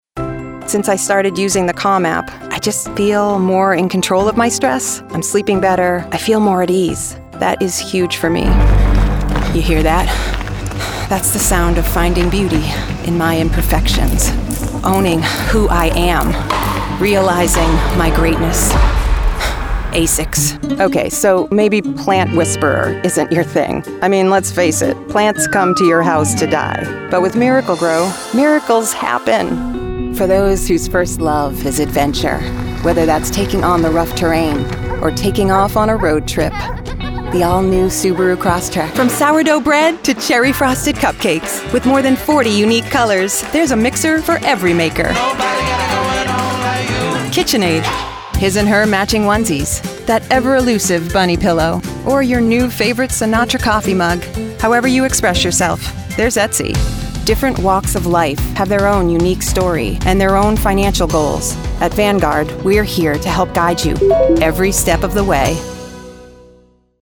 NATURAL, REAL, WARM. A VOICE YOU BELIEVE.